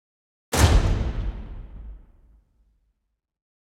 Suspense 1 - Stinger 2.wav